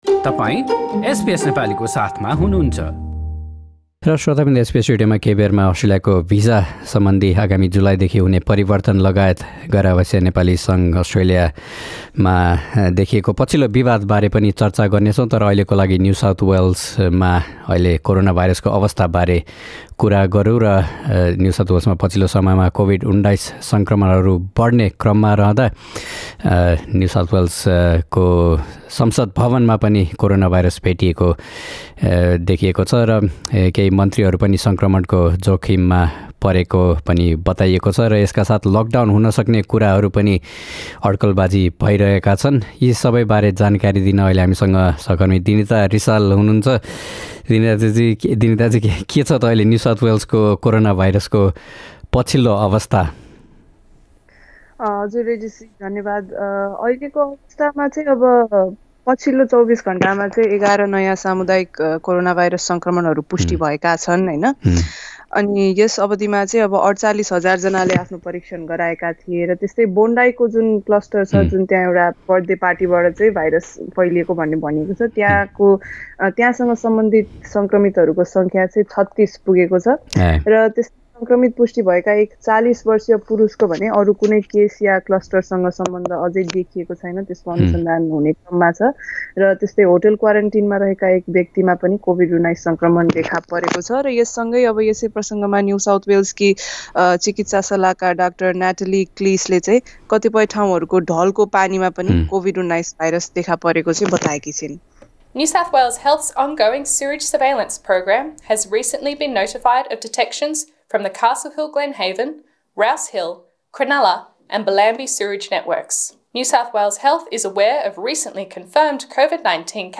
This news report is available in the Nepali language version of our website.